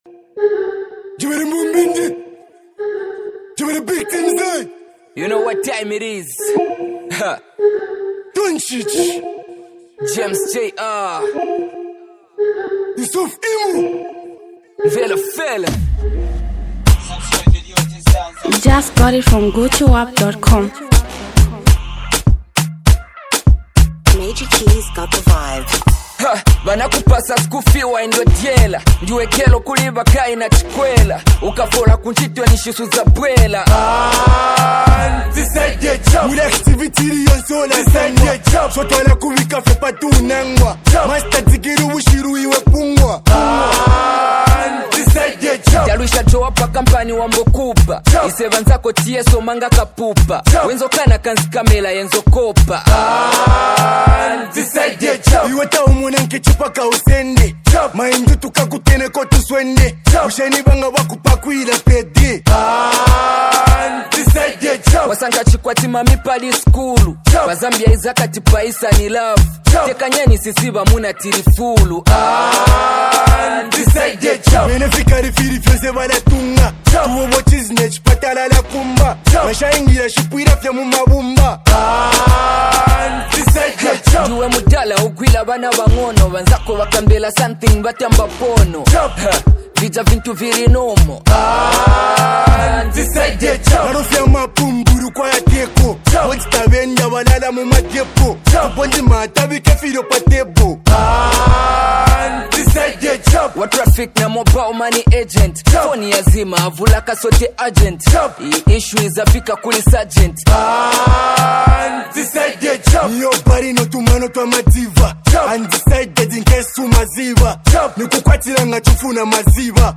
Zambian Mp3 Music
street anthem